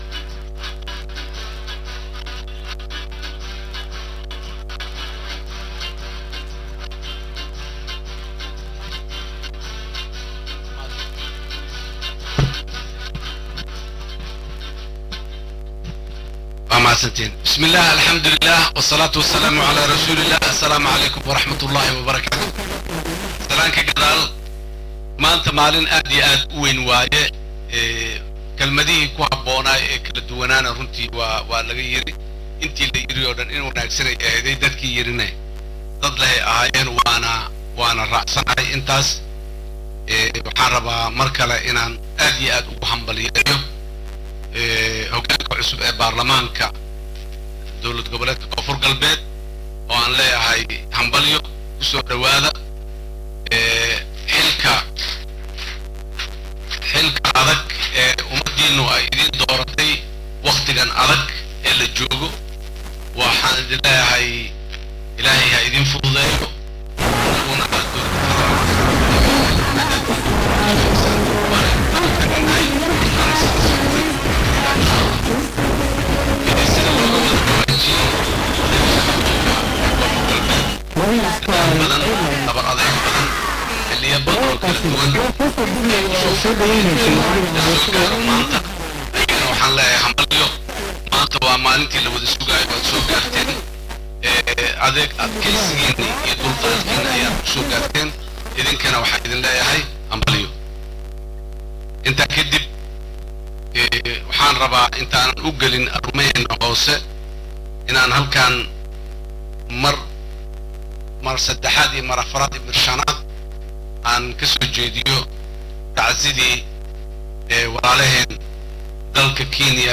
Dhageyso: Khudbadda Madaxweynaha Soomaaliya Ka Jeediyey Caleemo Saarka Gudoonka
Madaxweynaha Soomaaliya Xasan Sheekh Maxamuud oo khudbad ka jeediyay isla markaana ka sheekeeyay dadaaladii lagu soo dhisay Dowlada KGS iyo Baarlamankuba oo uu sheegay in mudo badan ay qaadatay dhisidiisa.